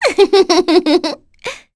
Pansirone-Vox_Happy1.wav